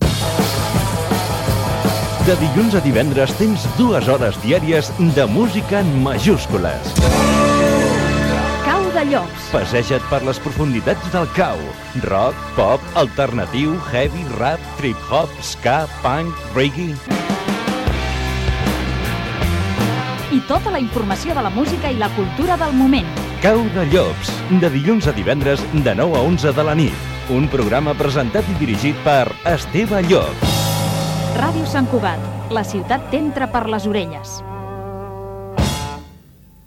Falca de promoció del programa.